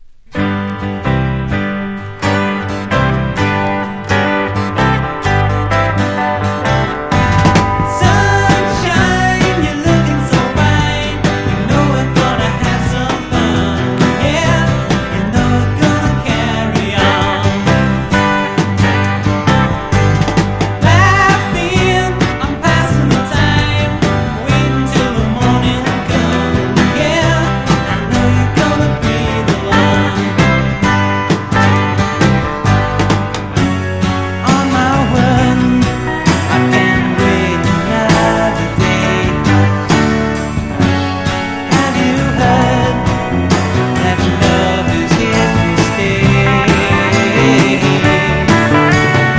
イノセントな歌声が素晴らしい青春SSW傑作。